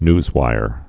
(nzwīr, nyz-)